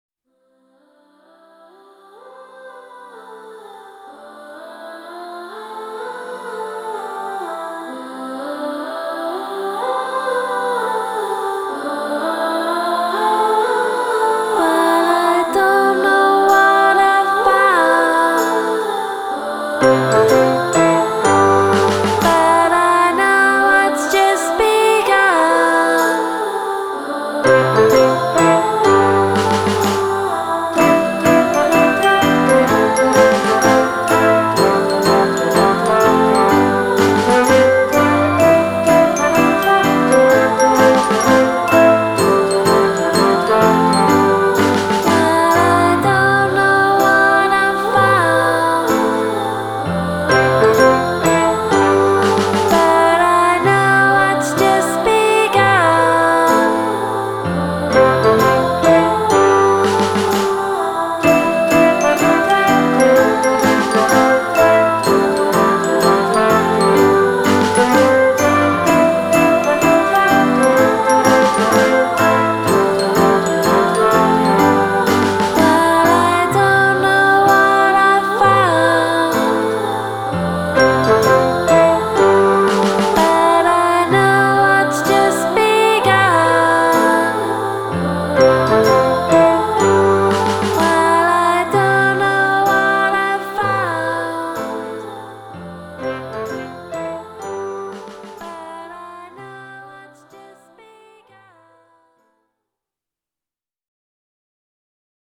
Genre: Indie Pop / Twee